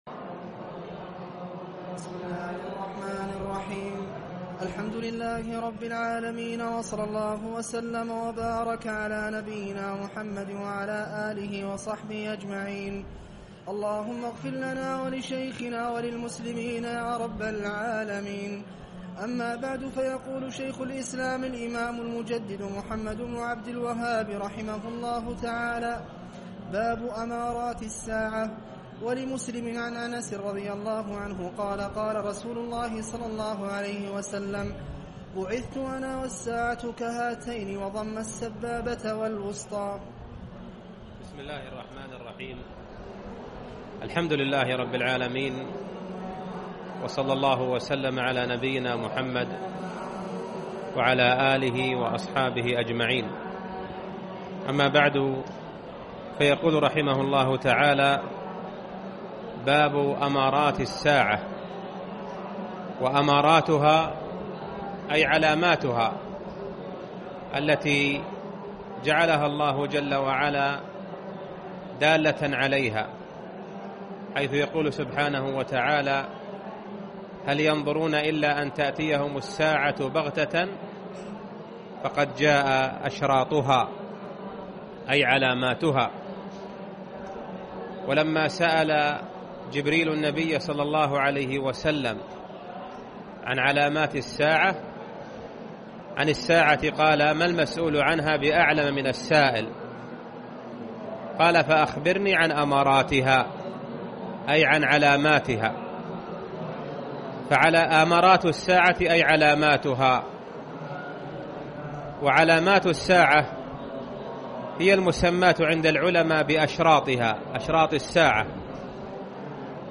الدرس الخامس - شرح أحاديث الفتن والحوادث